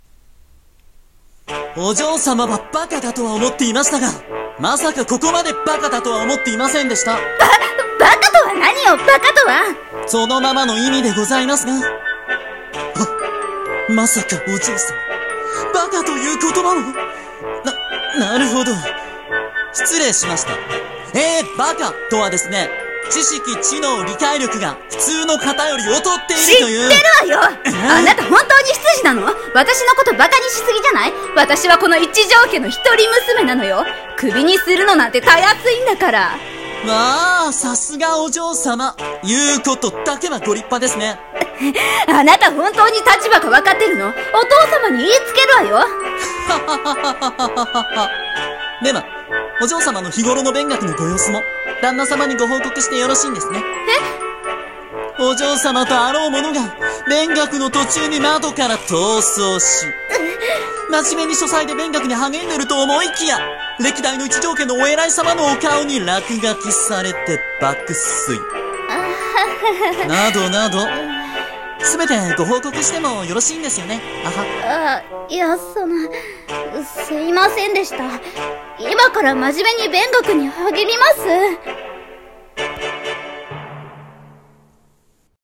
【声劇】 バカなお嬢様